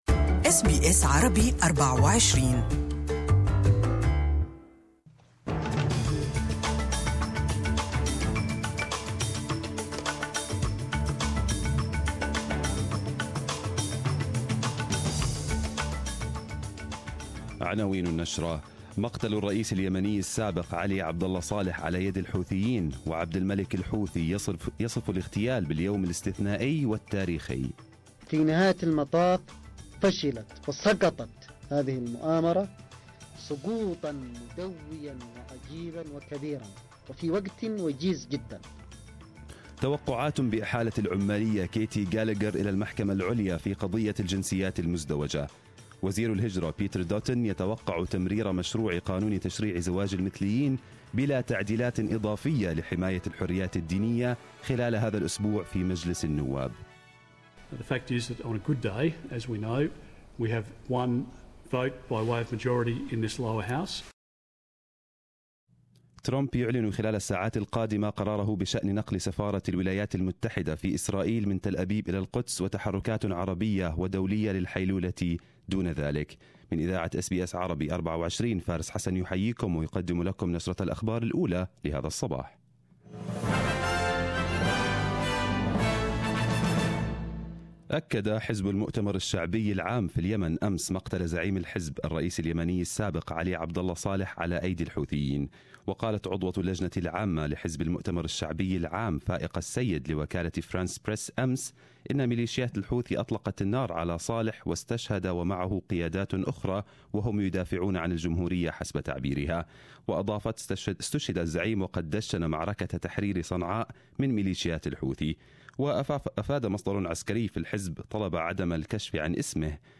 نشرة مفصّلة للأنباء من SBS عربي 24